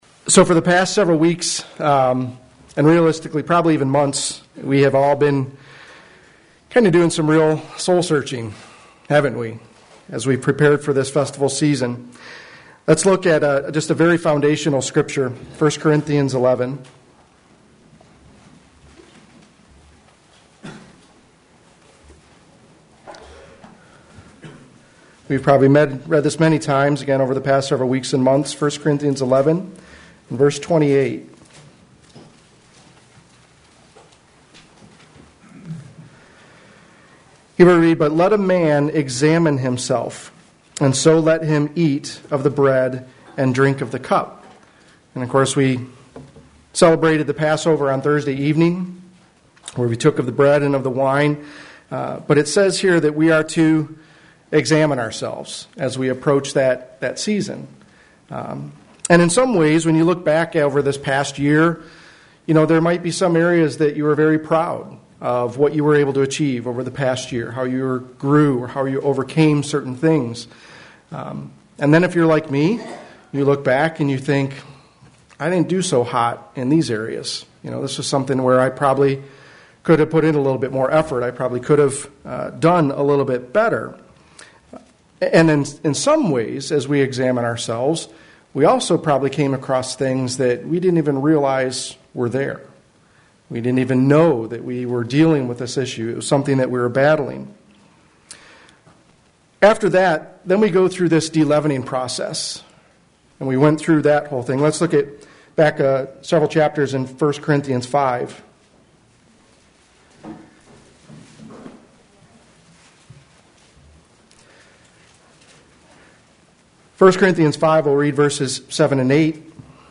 Sermons
Given in Flint, MI